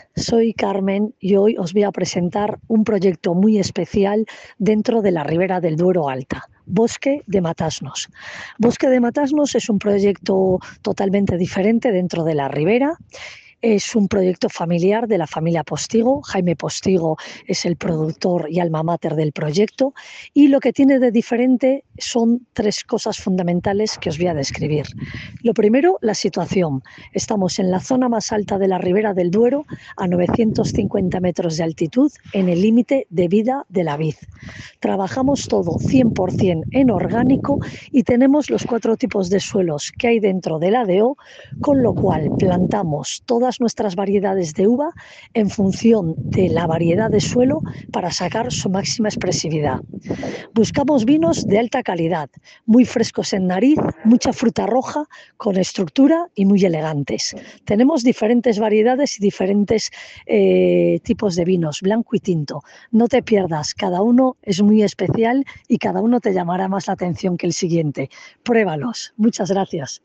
Detrás de cada botella hay alguien que habla, y queremos que los escuches. Nuestros bodegueros noscuentan aqui sus secretos, historias y anécdotas que hacen único cada vino… todo en apenas 20segundos, para no perder tu atención!